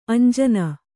♪ añjana